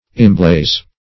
Imblaze \Im*blaze"\